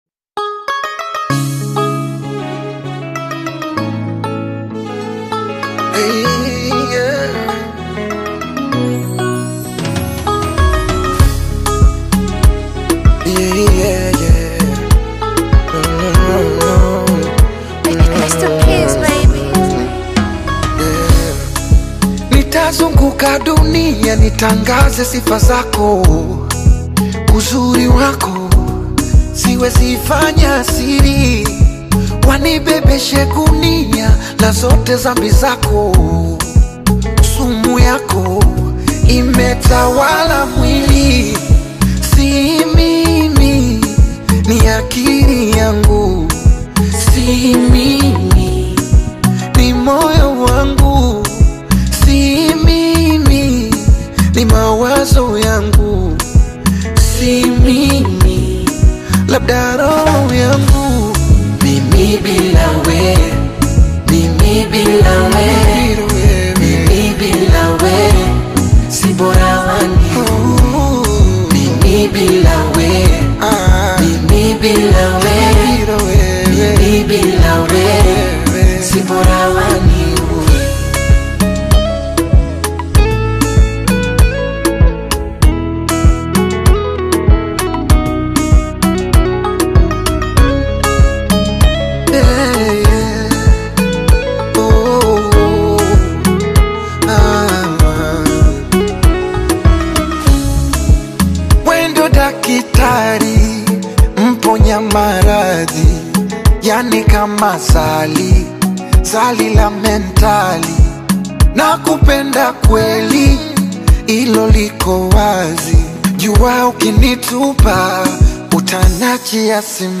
Talented Tanzanian Singer